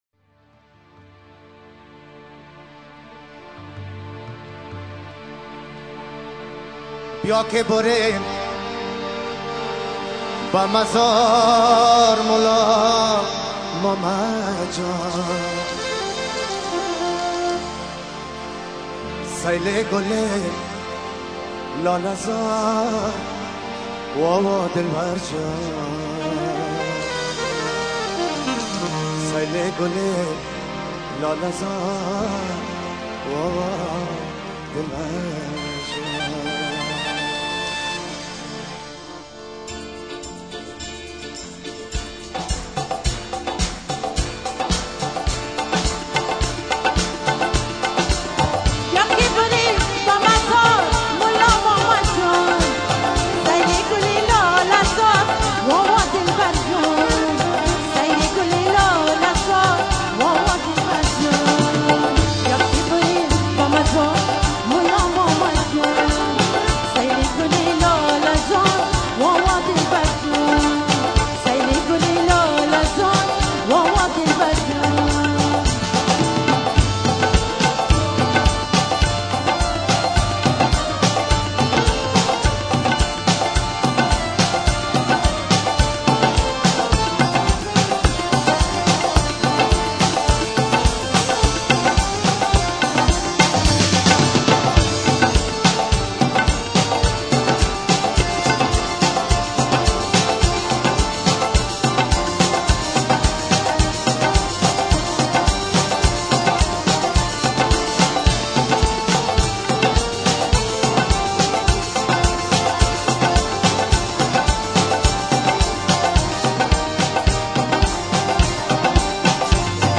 Concert (germany)